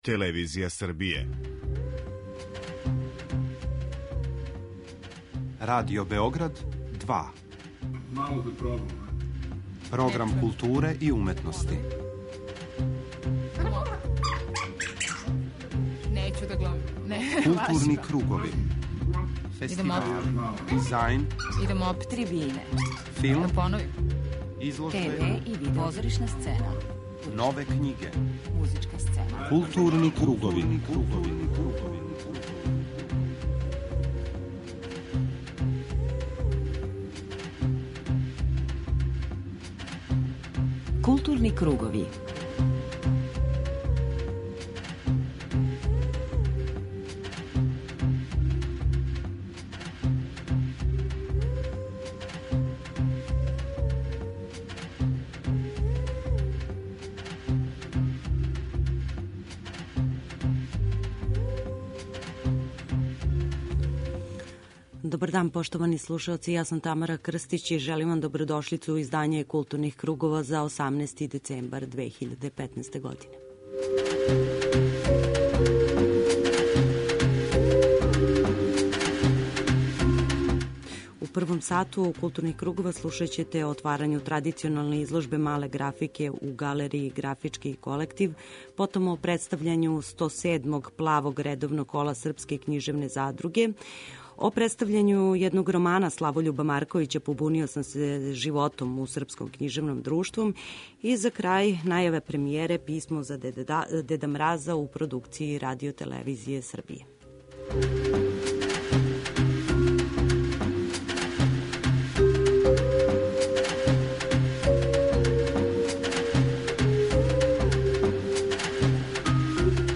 преузми : 41.10 MB Културни кругови Autor: Група аутора Централна културно-уметничка емисија Радио Београда 2.